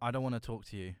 Voice Lines / Dismissive
i dont want to talk to you.wav